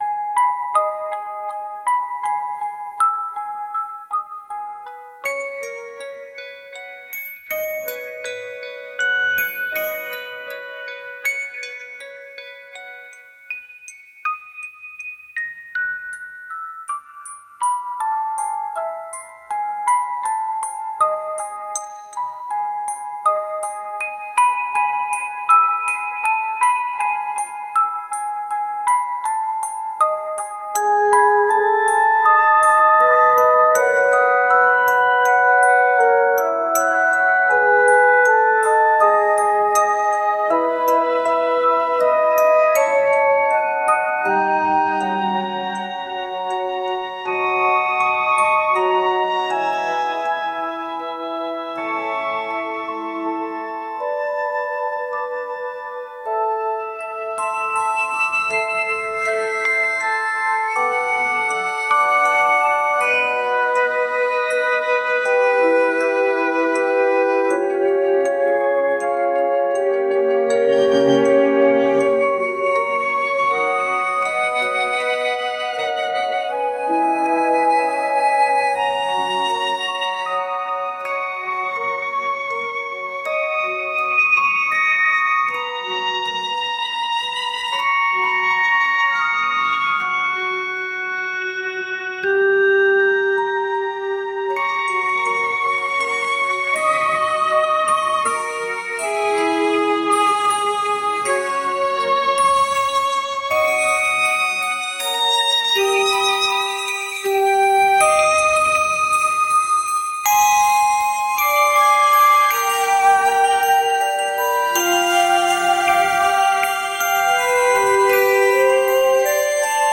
「大人な雰囲気」
「幻想的」